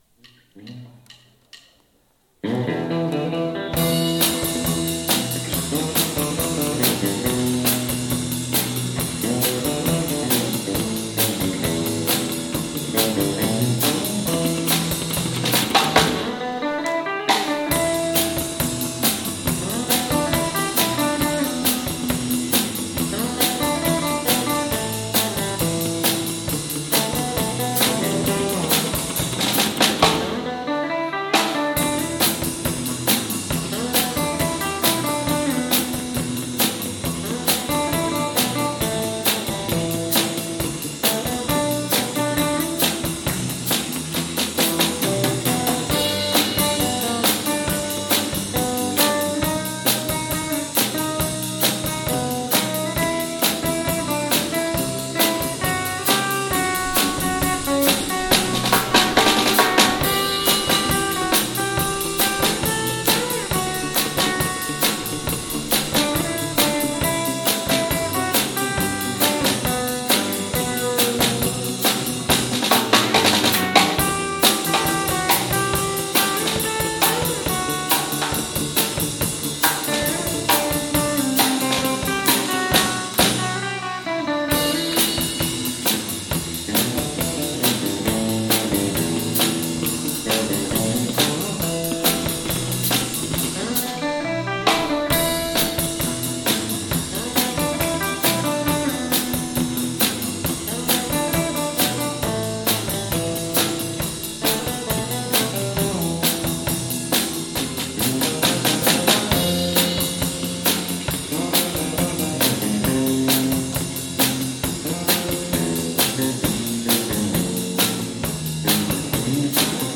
桂病院土曜コンサート-1 | The Arou Can